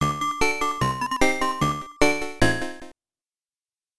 This music was recorded using the game's sound test.